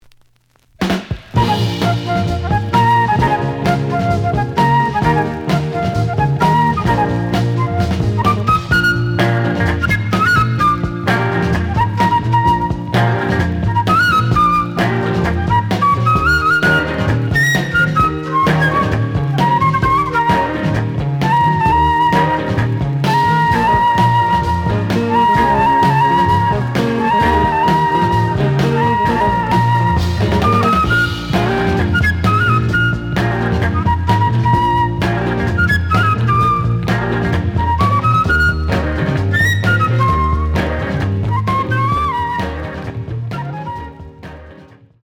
The audio sample is recorded from the actual item.
●Genre: Jazz Funk / Soul Jazz
Slight edge warp.